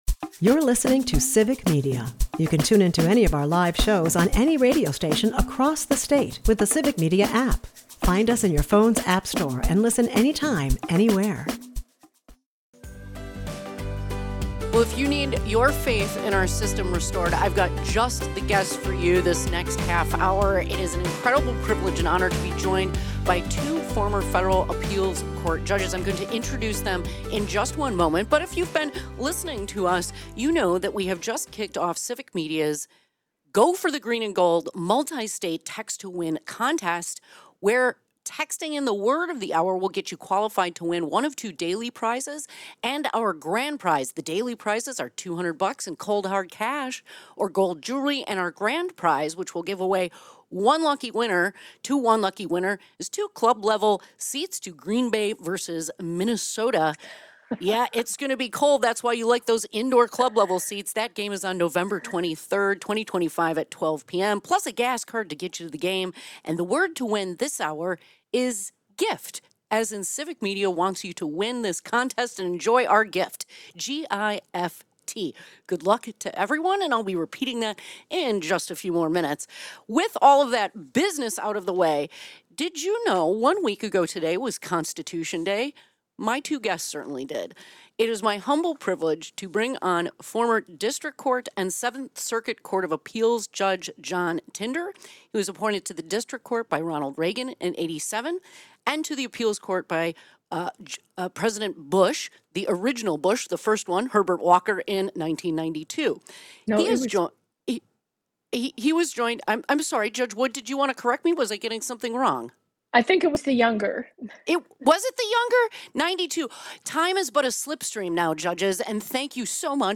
Judicial Independence & Trump’s UN Meltdown Guests: Diane Wood , John Daniel Tinder